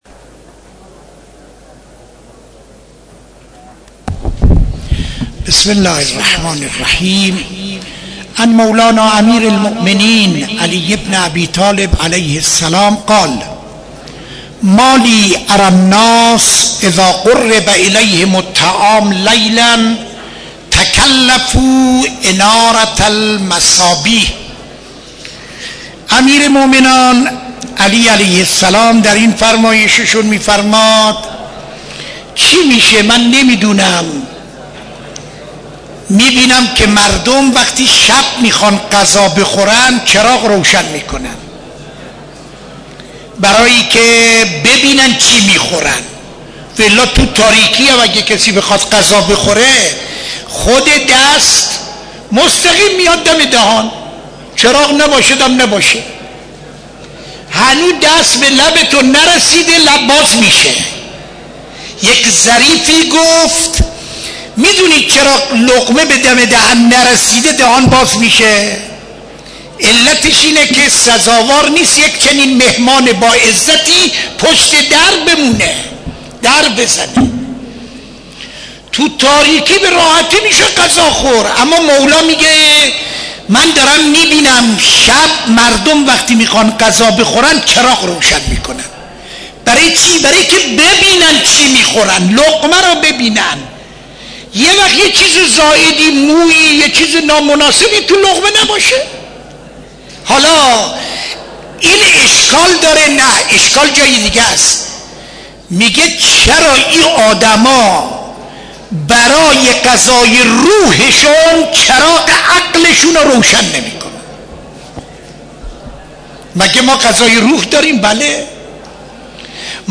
سخنرانی در مسجد دانشگاه (دوشنبه 28-07-93)